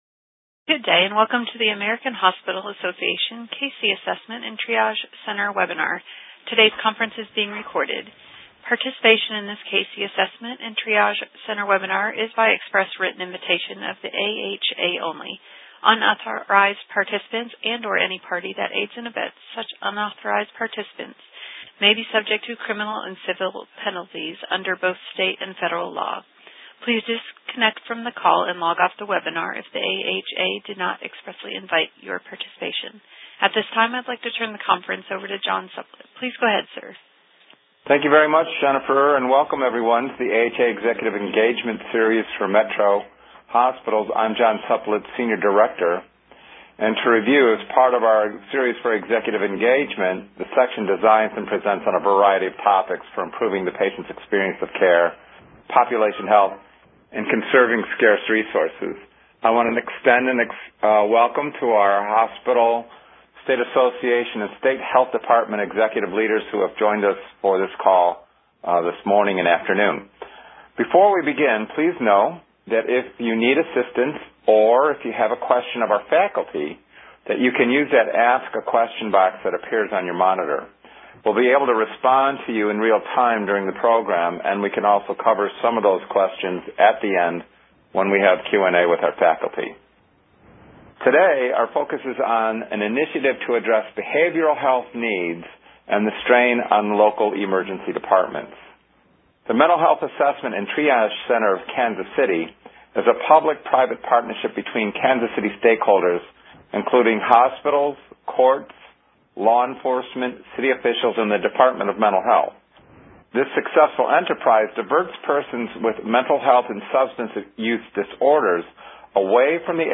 September 26, 2017 Webinar